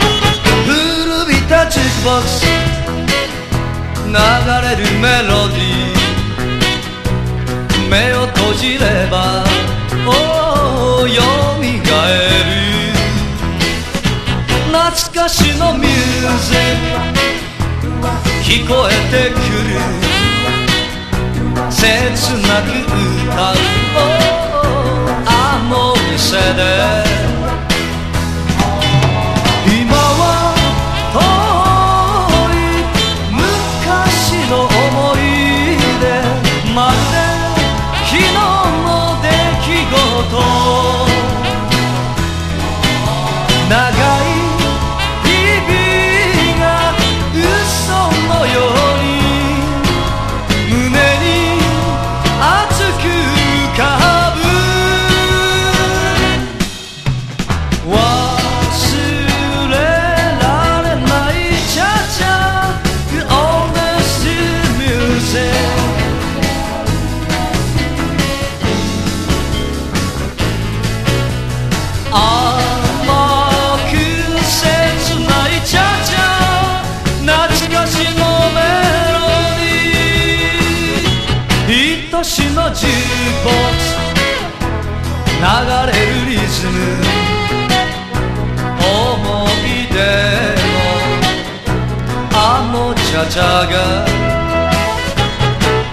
SWING (JPN)
華々しいホーンで幕を開けるゴージャス＆ロマンティックな日本語ブギウギ・スウィング